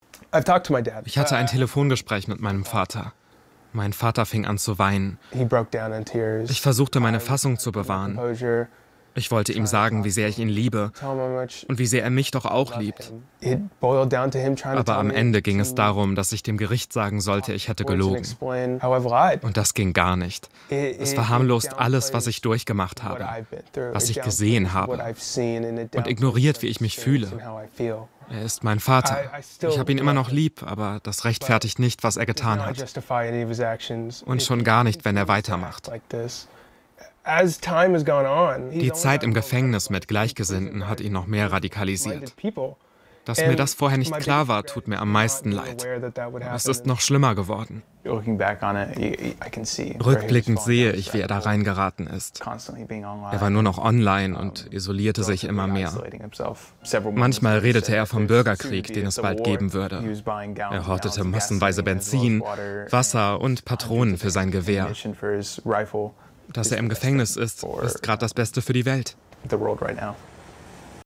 hell, fein, zart
Jung (18-30)
Lip-Sync (Synchron), Trick, Comedy